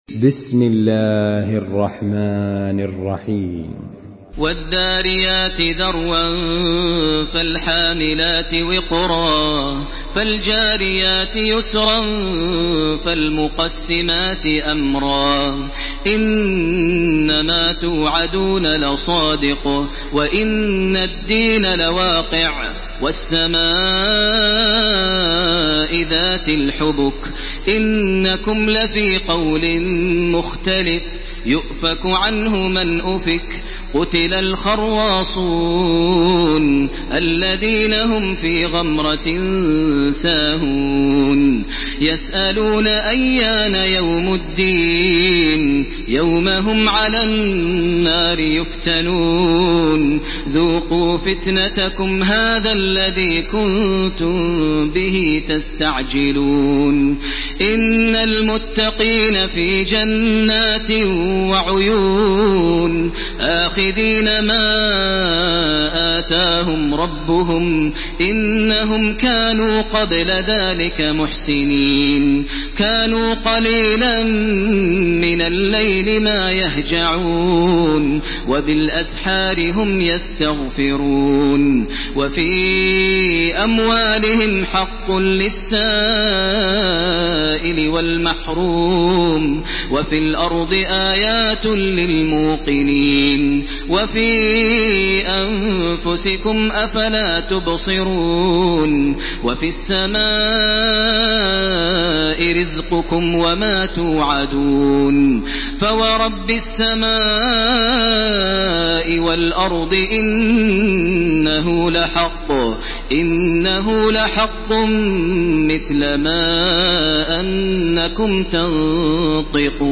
تراويح الحرم المكي 1429
مرتل